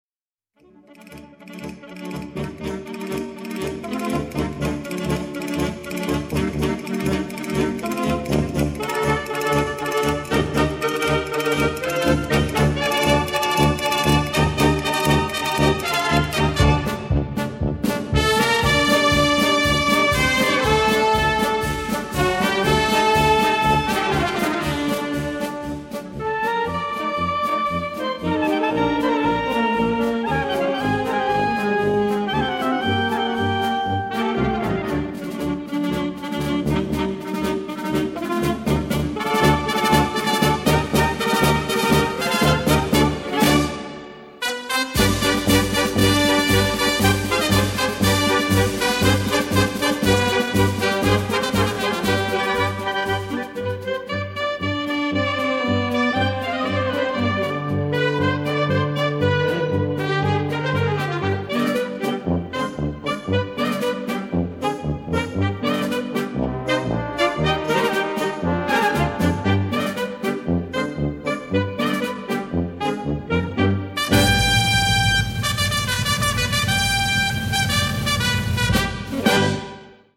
PASO DOBLE